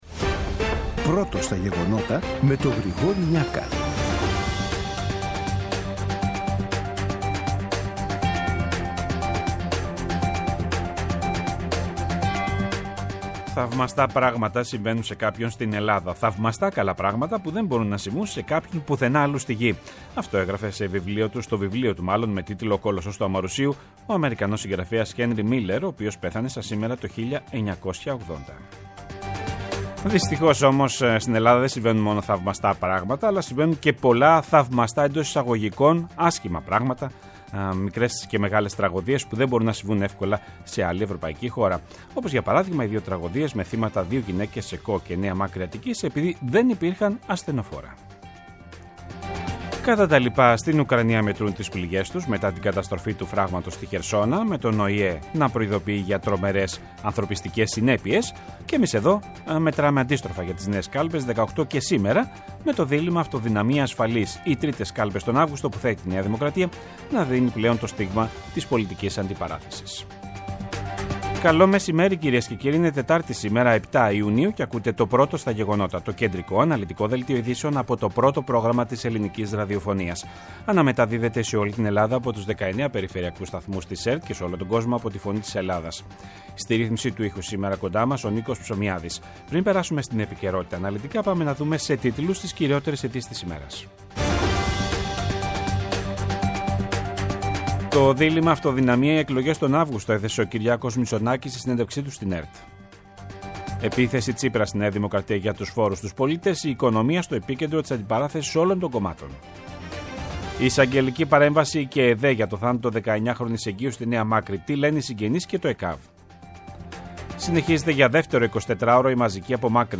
Το κεντρικό ενημερωτικό μαγκαζίνο του Α΄ Προγράμματος, από Δευτέρα έως Παρασκευή στις 14.00. Με το μεγαλύτερο δίκτυο ανταποκριτών σε όλη τη χώρα, αναλυτικά ρεπορτάζ και συνεντεύξεις επικαιρότητας.